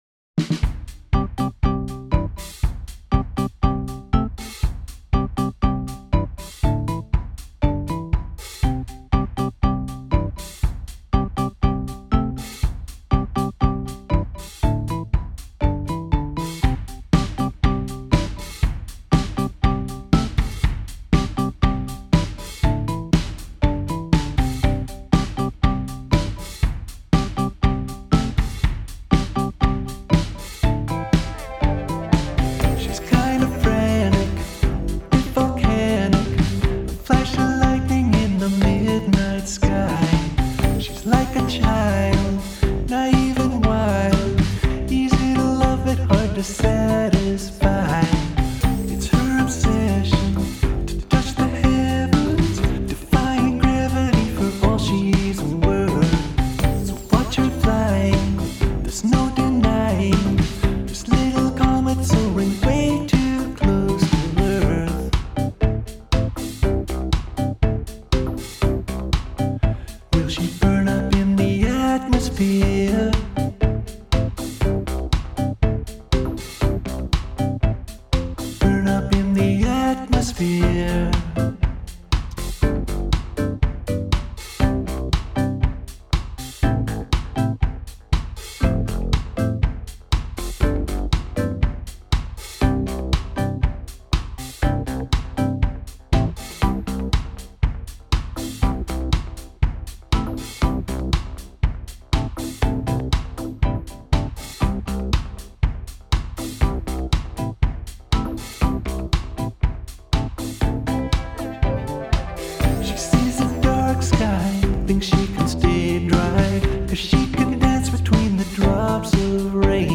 This one is about as pop as I can get without losing all artistic integrity. It is also my first experiment with hard core auto-tuned vocals.